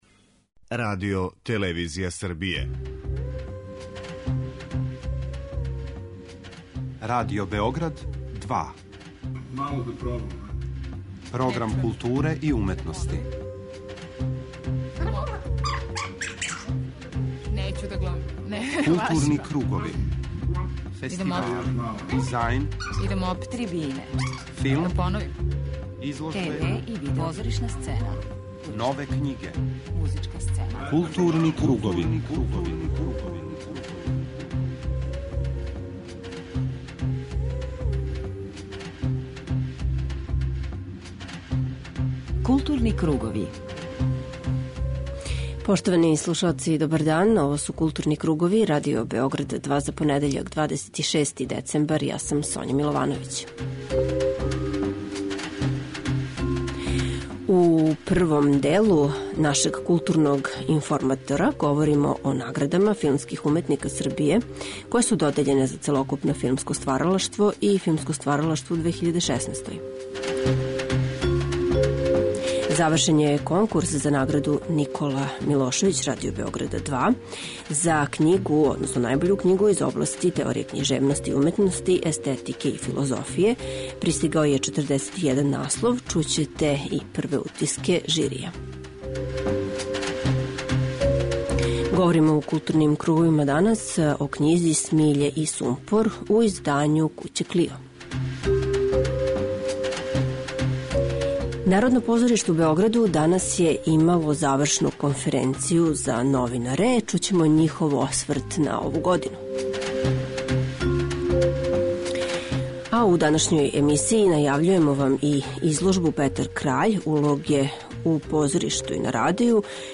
У последњем тематском блоку Арс сонора у 2016. години, приказаћемо концерте: бугарског хора "Филип Кутев", који је гостовао у Београду 21. децембра, Београдске филхармоније - из циклуса "За заљубљене", и Солиста, хора и Симфонијског оркестра РТС-a, који је прошле суботе извео Брамсов Реквијем, под управом нашег славног диригента Младена Јагушта.